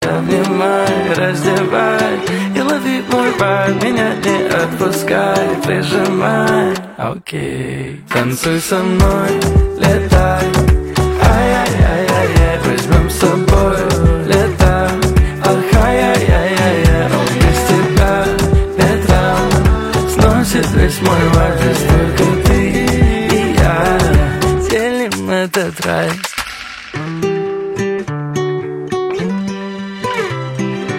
поп , танцевальные , романтические
мелодичные